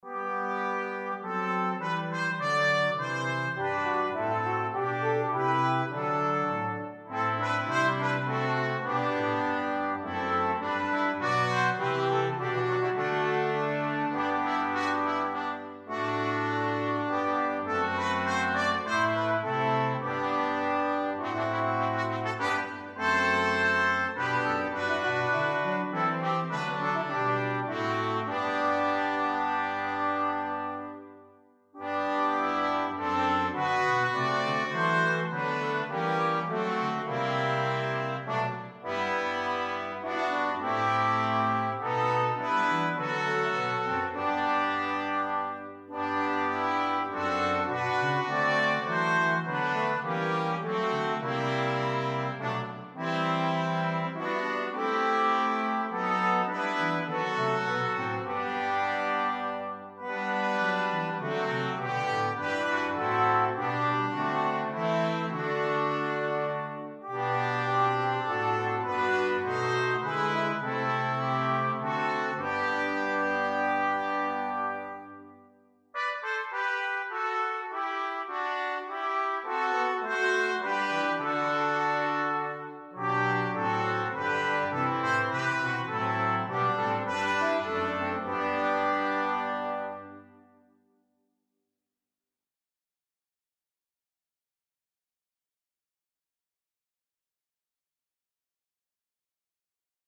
Koperkwintet – mp3